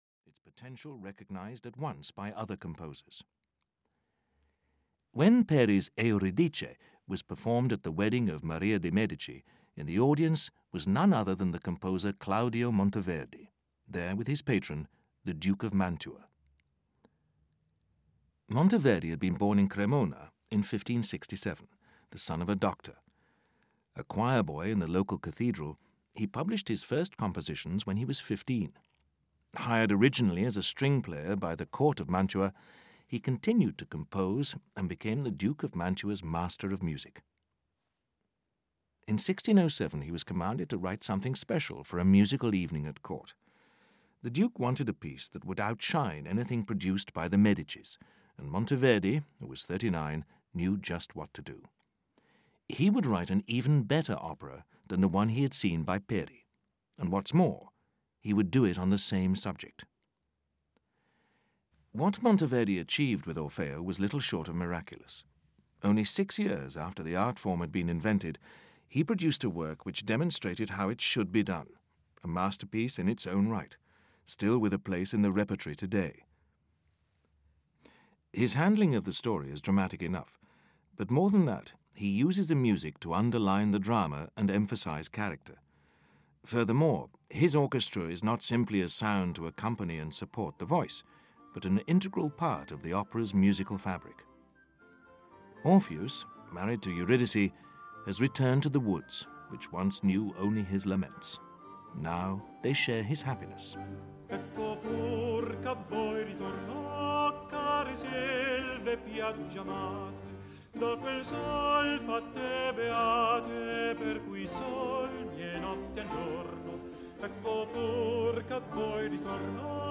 Ukázka z knihy
Featuring more than seventy-five musical examples.
• InterpretRobert Powell